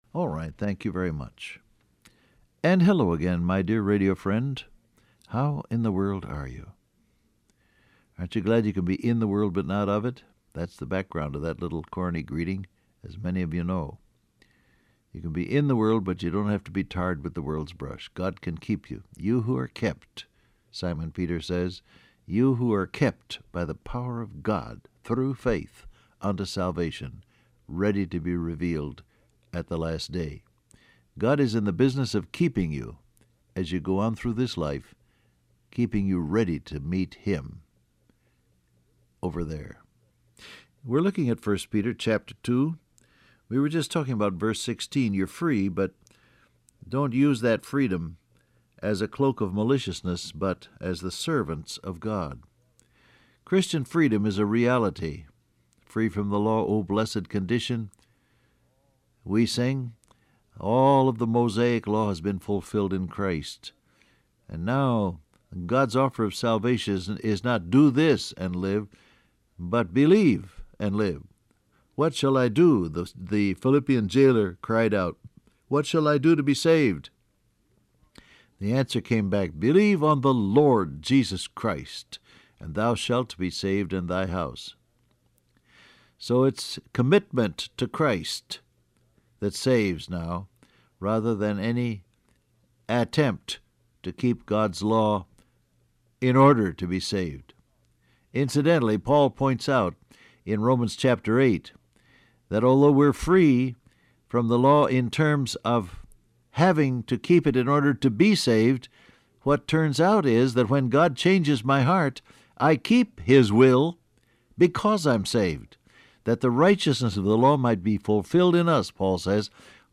Download Audio Print Broadcast #7140 Scripture: 1 Peter 2:16-17 , Romans 8 Topics: Care , Honor , Value Transcript Facebook Twitter WhatsApp Alright, thank you very much.